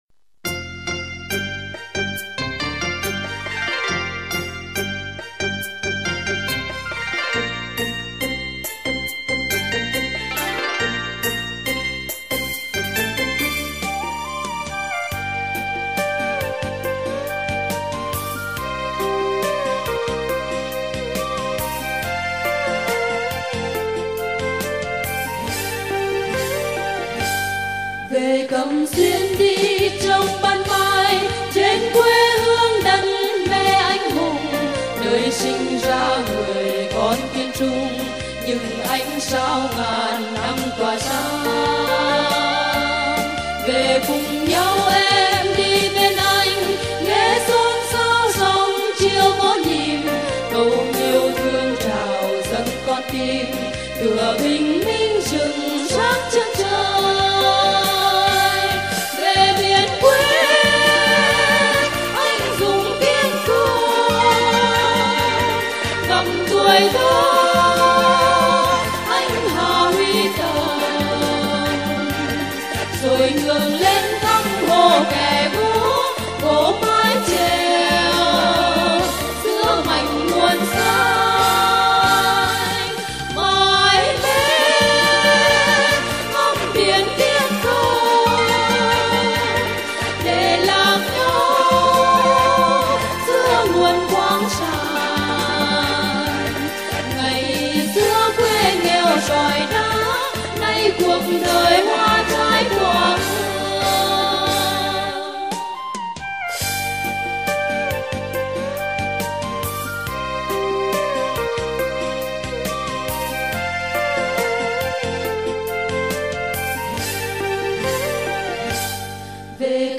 thuộc thể loại Dân ca trữ tình quê hương.